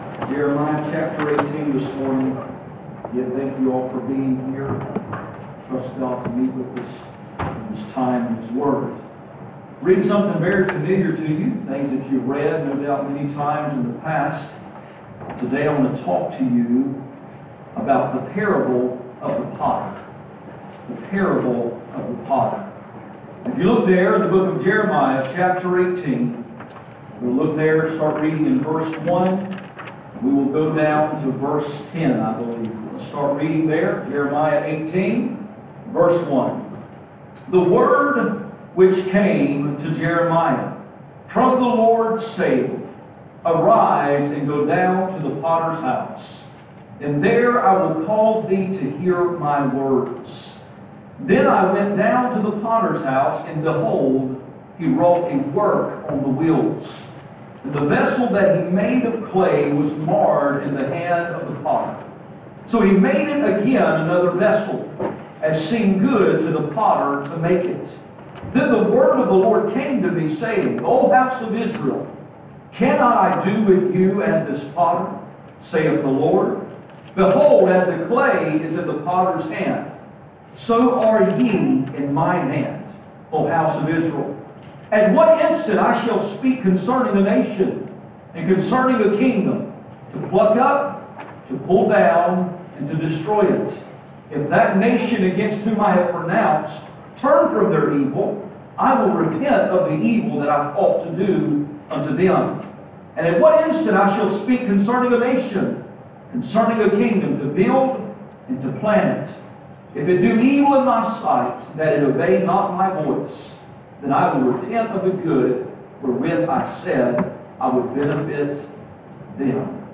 Jeremiah 18:1-10 Service Type: Sunday Morning %todo_render% « Christian certainties part 3 The parable of the potter